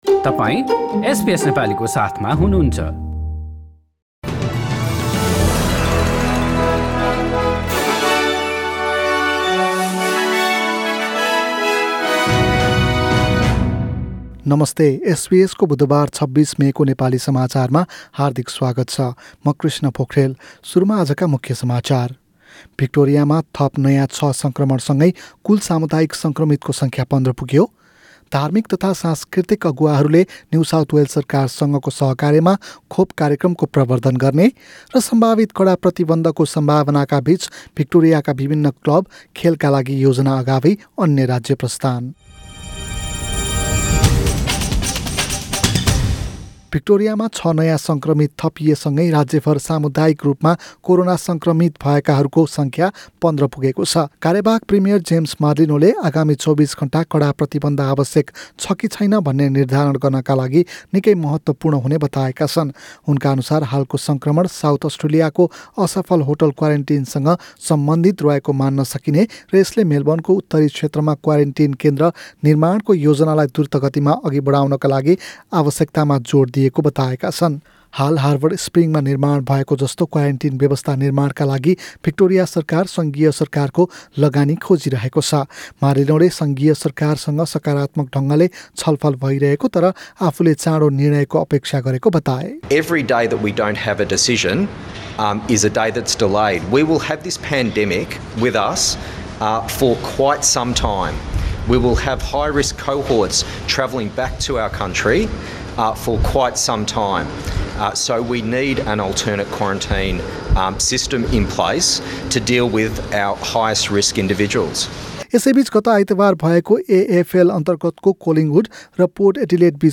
एसबीएस नेपाली अस्ट्रेलिया समाचार: बुधबार २६ मे २०२१